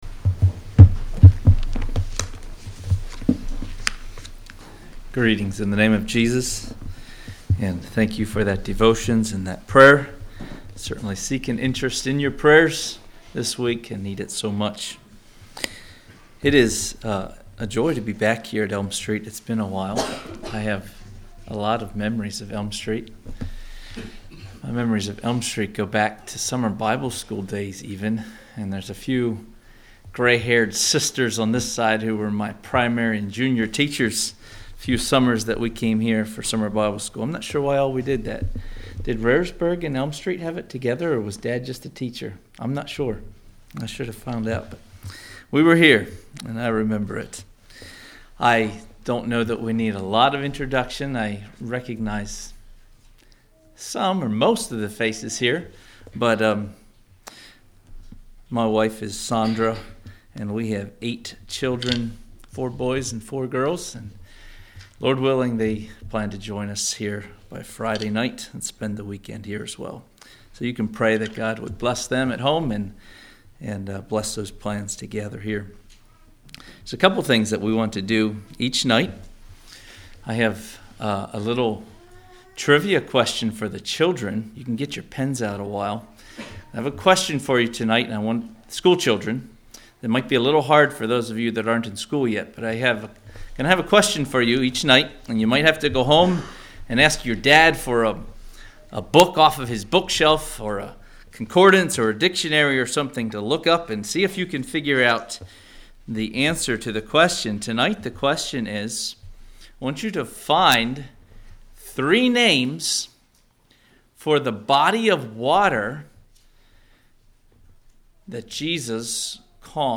2021 Sermon ID